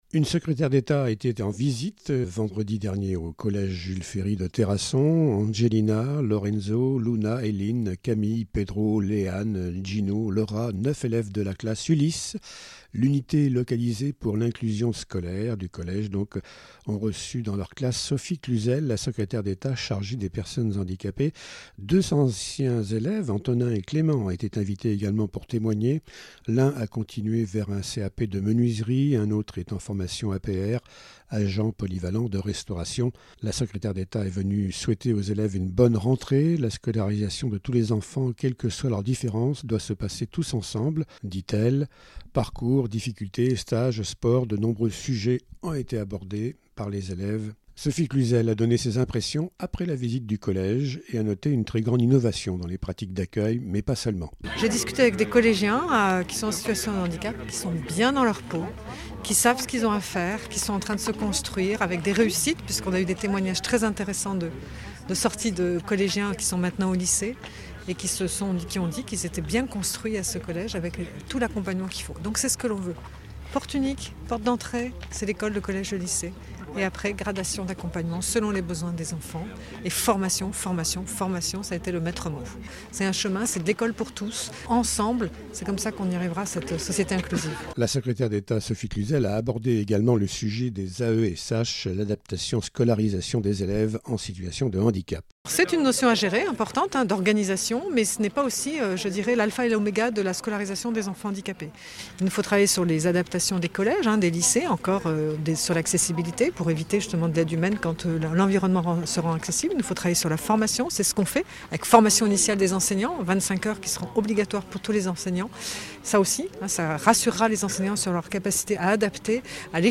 Reportage audio